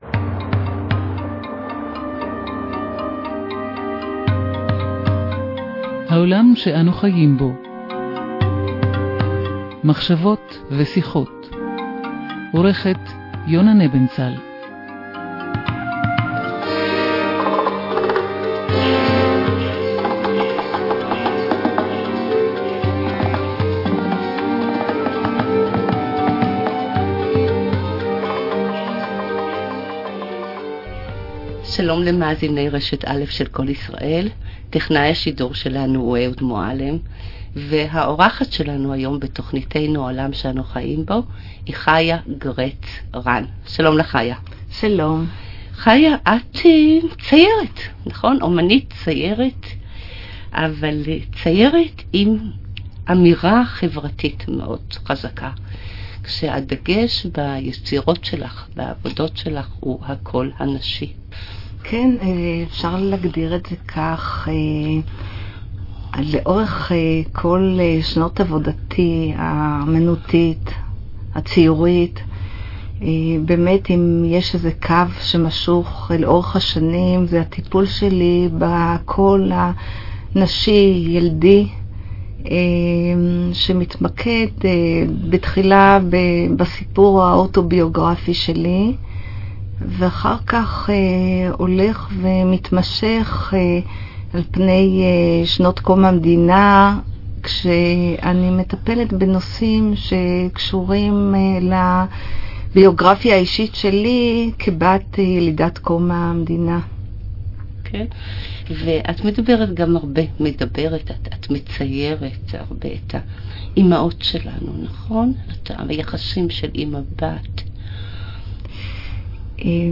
ראיון רדיו – רשת א